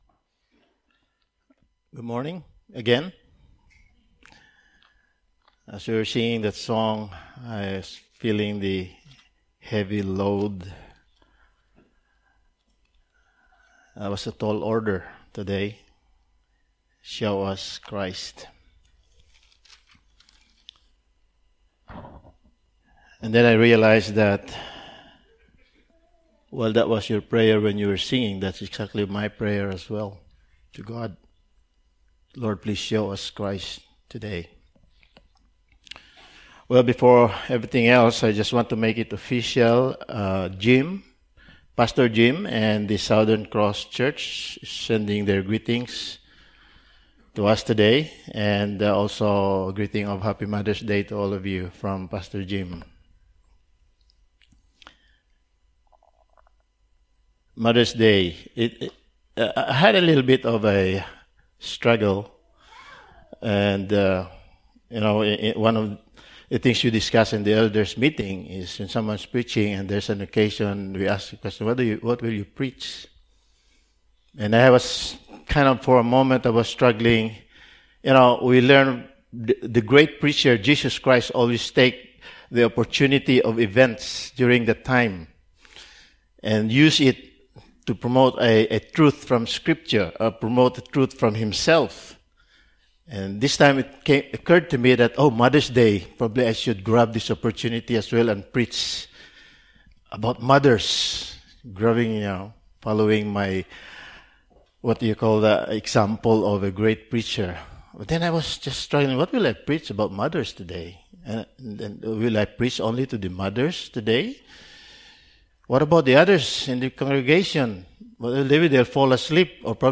Passage: John 10:1-21 Service Type: Sunday Morning